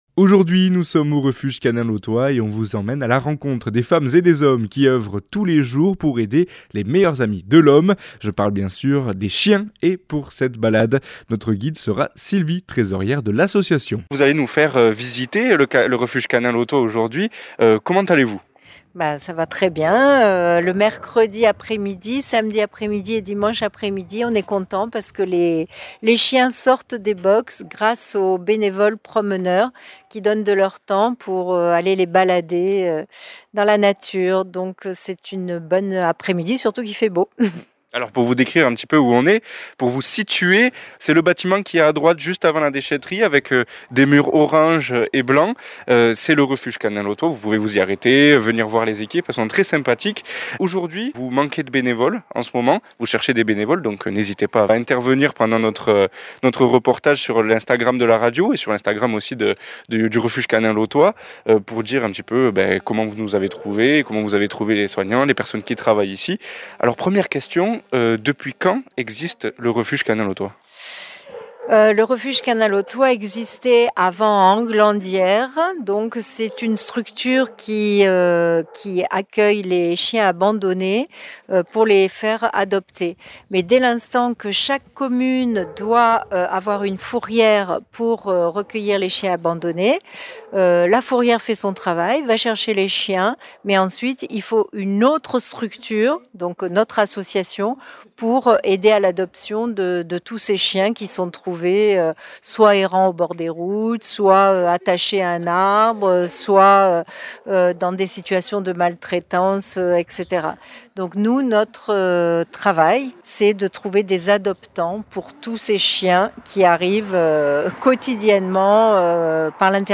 On vous emmène au refuge canin lotois pour un reportage exclusif !